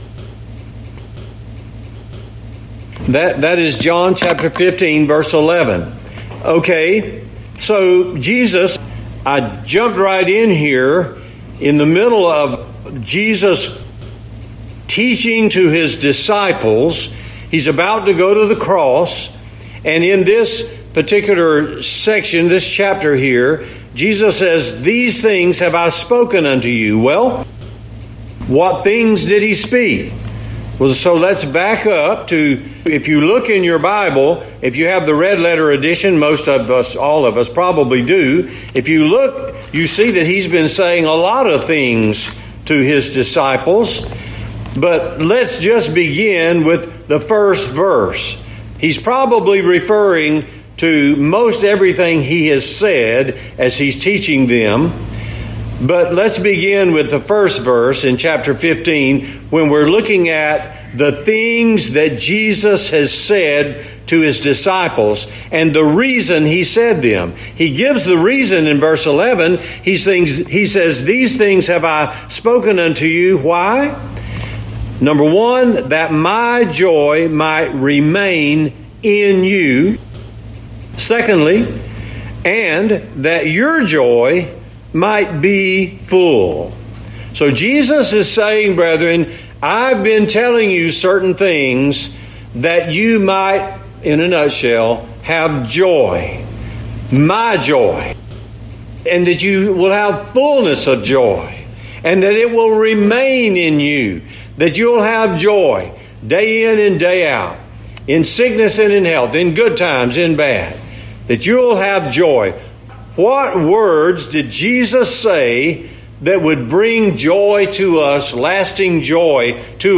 John 15:11, Joy From Words Of Jesus Oct 1 In: Sermon by Speaker